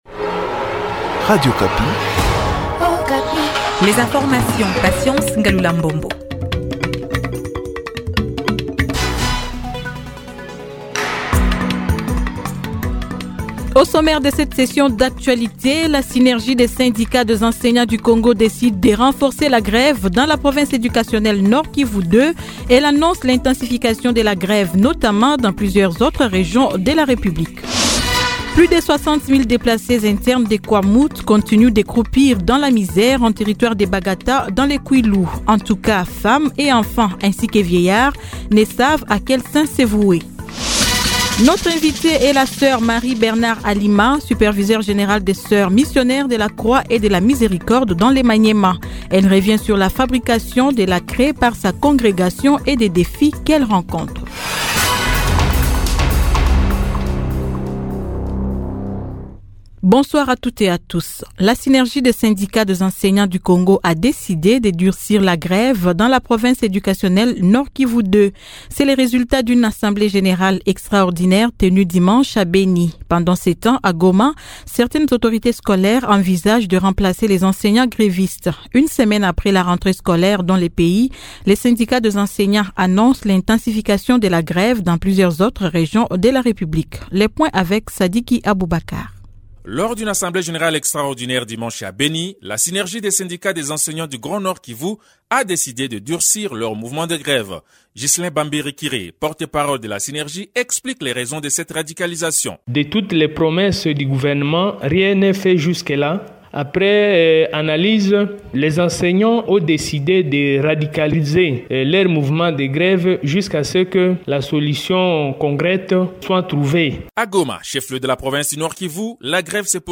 Edition soir 18H BENI : Radicalisation de la grève par les enseignants ; situation dans le pays. BENI : Reportage dans une école.